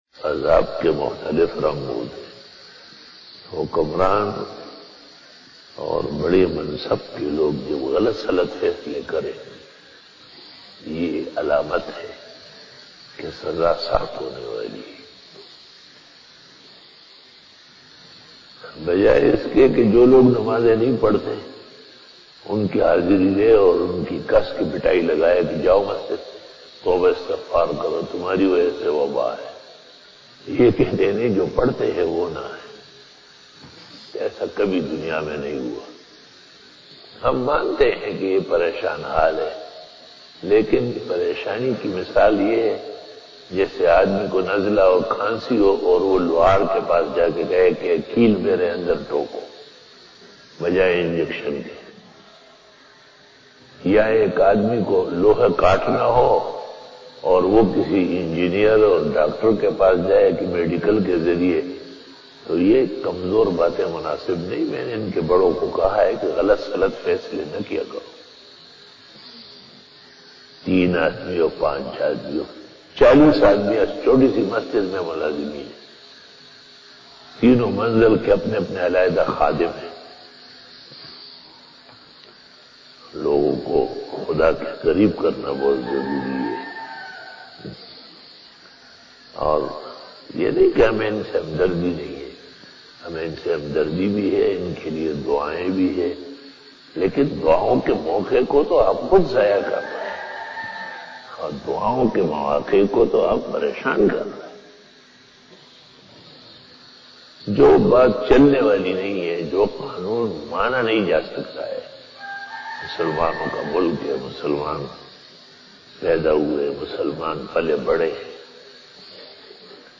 After Fajar Byan
بیان بعد نماز فجر بروز جمعہ